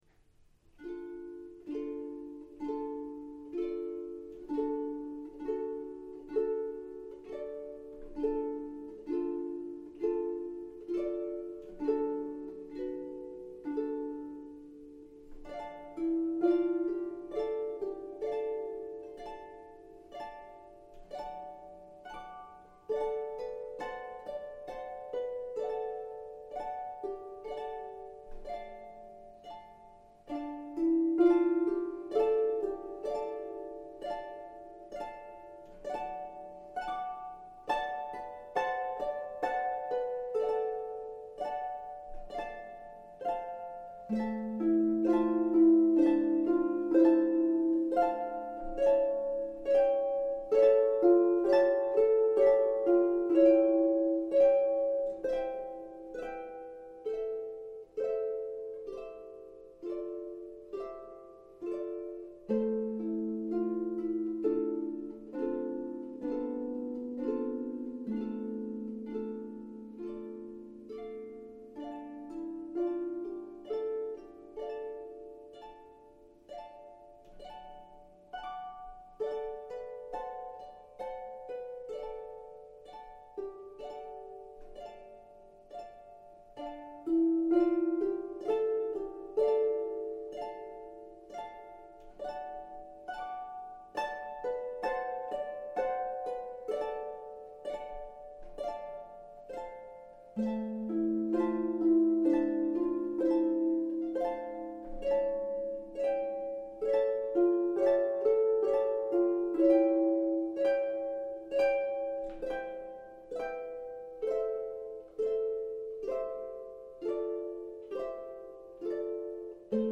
solo harp demo files